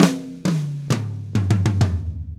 Drumset Fill 09.wav